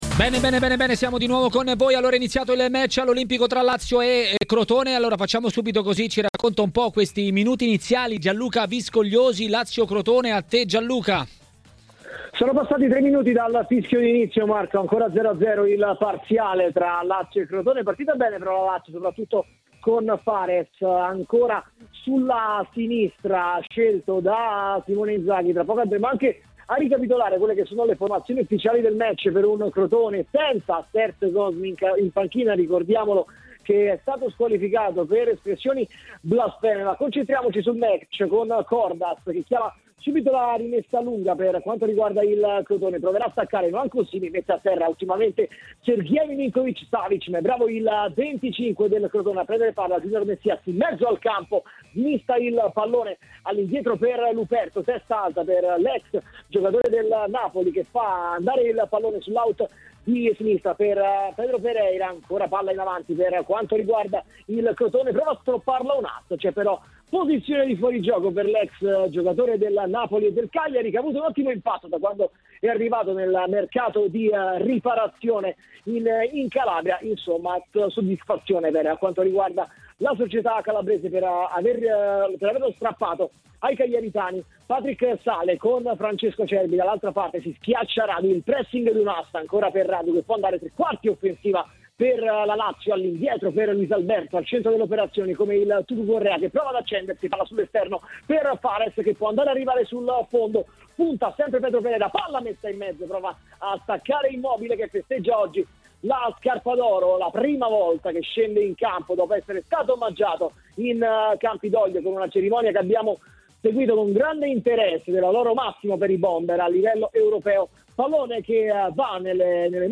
A dire la sua sui temi di giornata a TMW Radio, durante Maracanà, è stato l'ex calciatore e tecnico Alessio Tacchinardi.